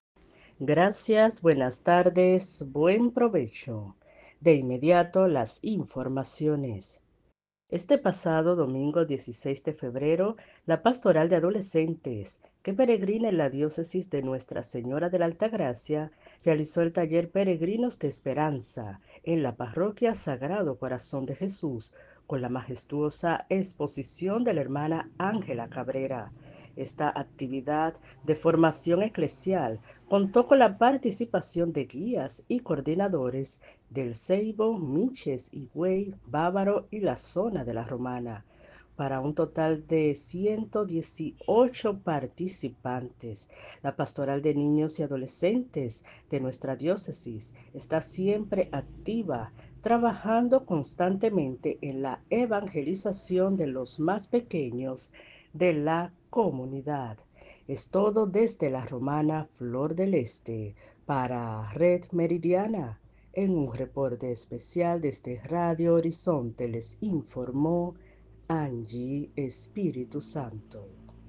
Reportaje para la Red Meridiana